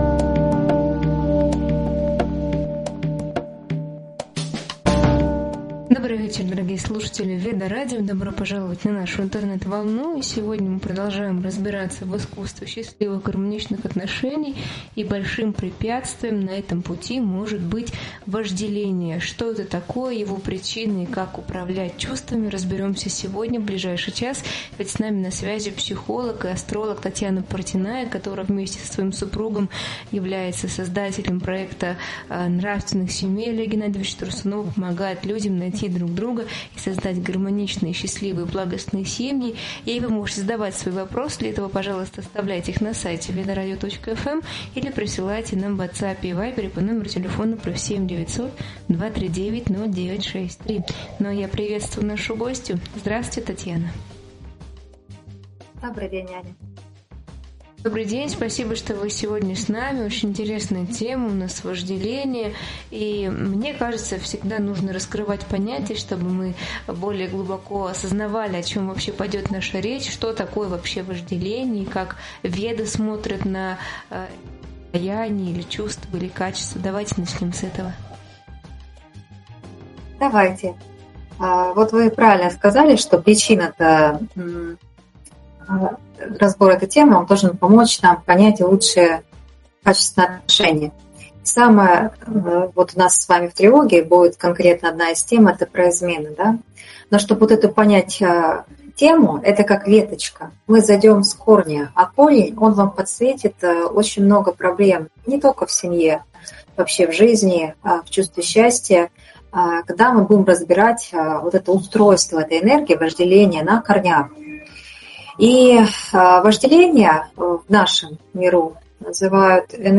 психолог и астролог